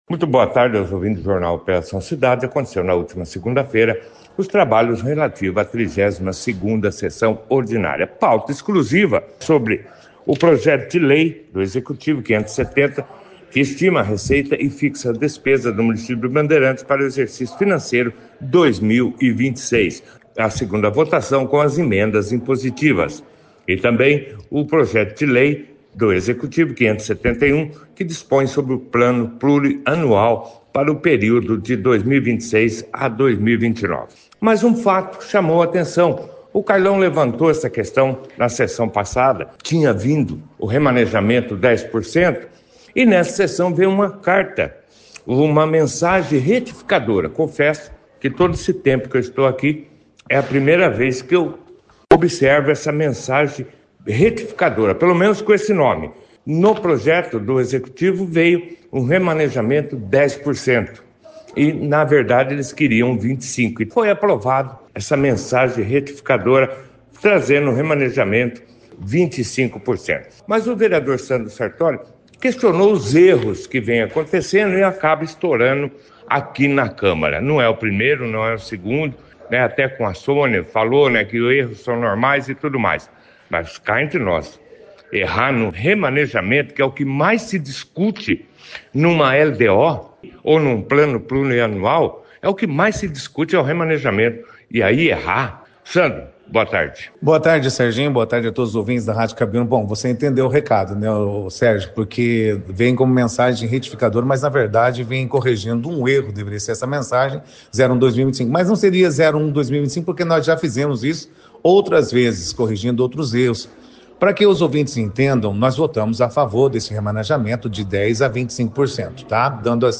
A 32ª Sessão Ordinária da Câmara Municipal de Bandeirantes foi destaque na 2ª edição do Jornal Operação Cidade desta terça-feira, dia 21 de outubro. O destaque foi para os projetos de lei do Executivo número 570 e 571, que tratam da Lei Orçamentária Anual (LOA) de 2026 e do Plano Plurianual (PPA) para o período de 2026 a 2029.
Os vereadores Sandro Sartorio e o presidente da Casa Gustavo do Té, participaram falando sobre uma mensagem retificadora apresentada do Executivo, corrigindo um erro no percentual de remanejamento orçamentário, que passou de 10% para 25%.